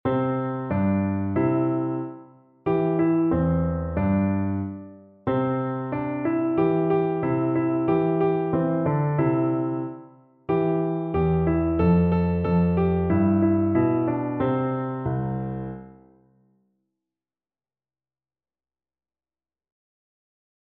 No parts available for this pieces as it is for solo piano.
Moderato =c.92
2/4 (View more 2/4 Music)
Piano  (View more Easy Piano Music)